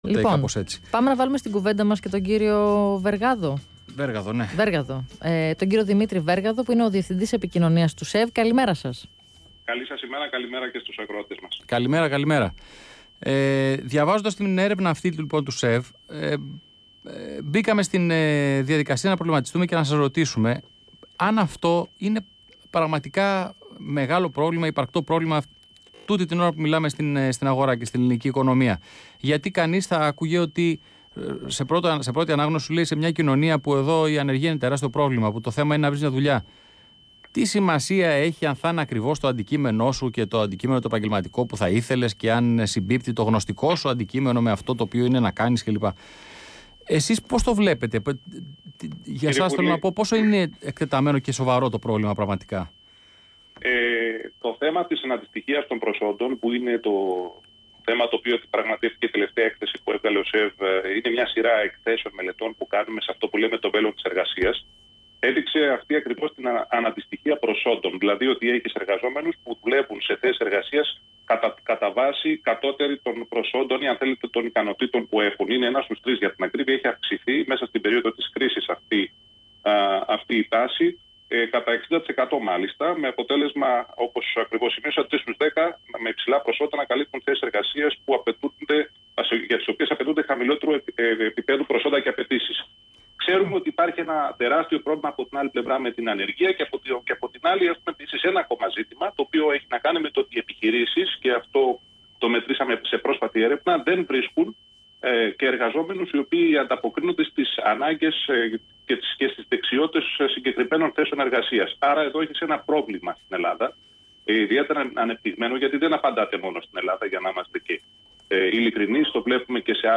Ραδιοφωνική συνέντευξη